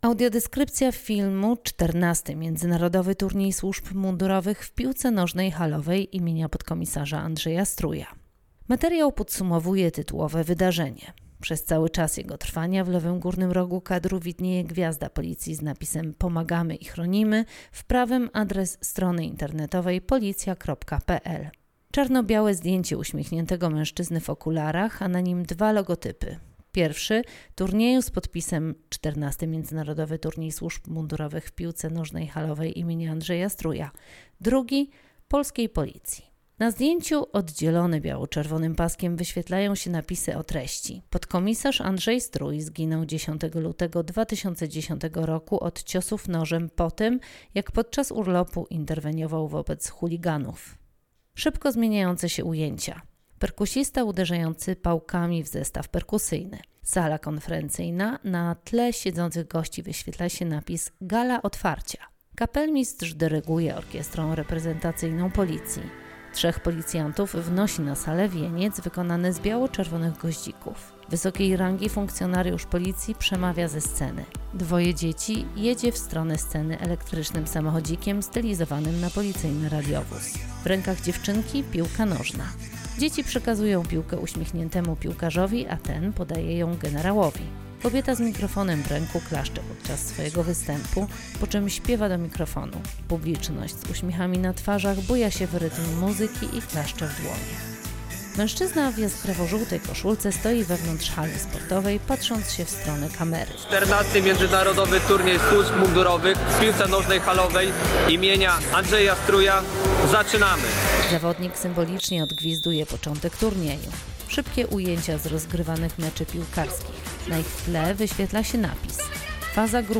Nagranie audio Audiodeskrypcja do filmu: Finał XIV Międzynarodowego Turnieju Służb Mundurowych w Piłce Nożnej Halowej im. podkom. Andrzeja Struja